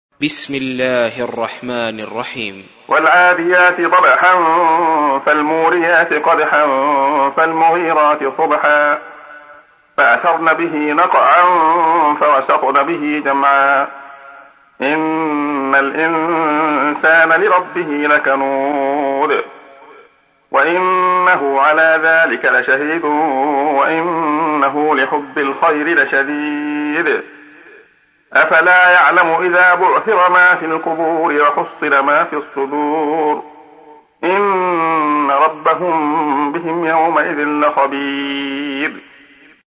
تحميل : 100. سورة العاديات / القارئ عبد الله خياط / القرآن الكريم / موقع يا حسين